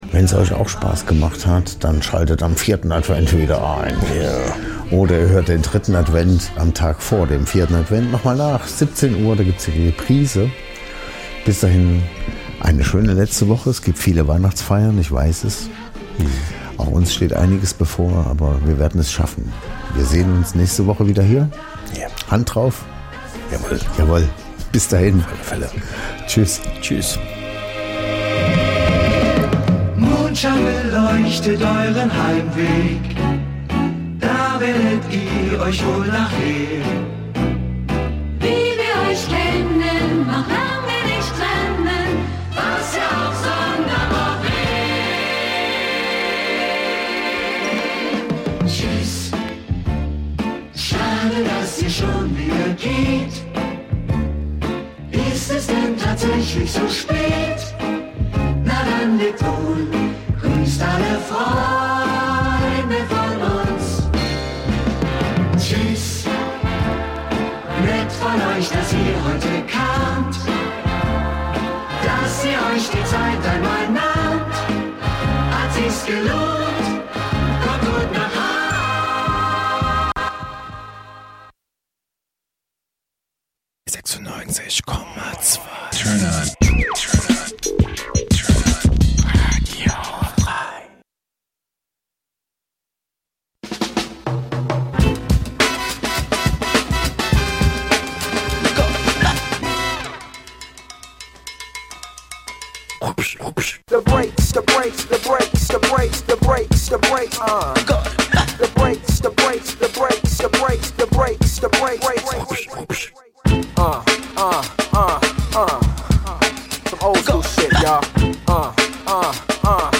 ist ausgesprochener HIP HOP, JAZZ, FUNK & SOUL Fan und produziert selbst Beats und Remixe.
BLUES, LATIN, ELECTRO, REGGAE und POP